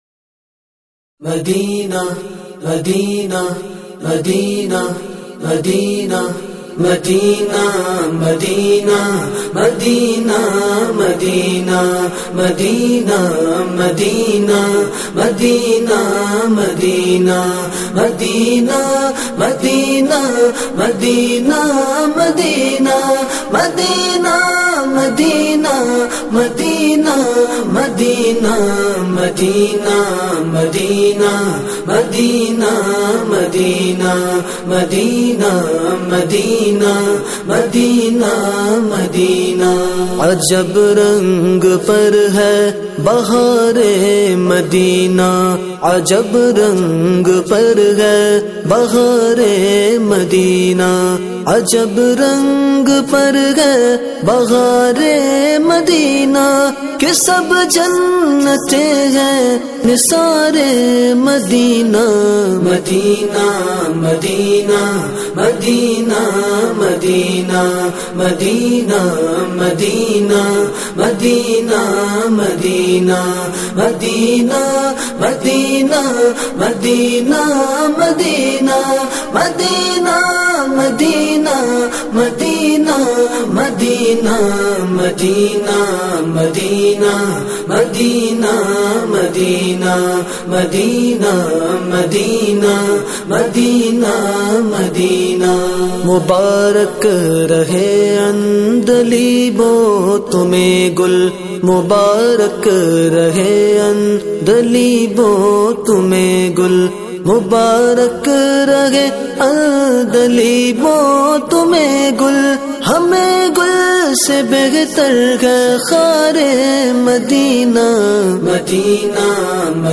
Category : Naat | Language : UrduEvent : 10th Album